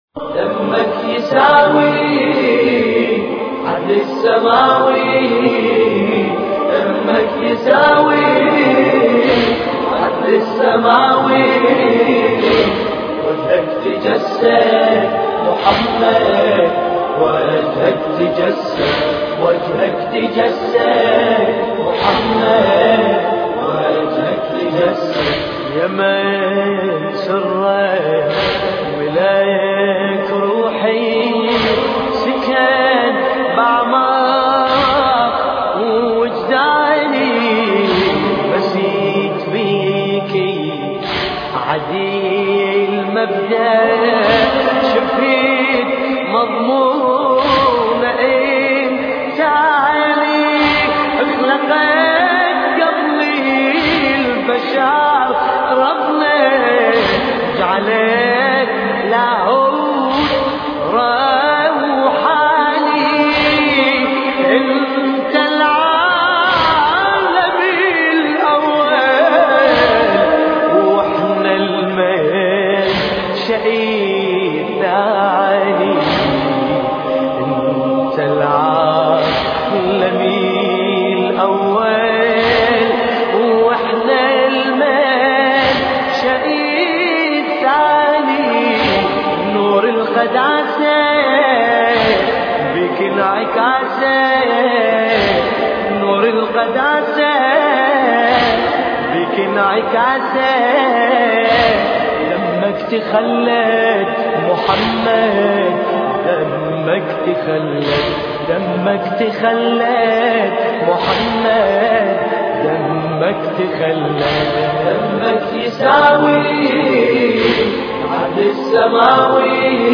مراثي الامام الحسين (ع)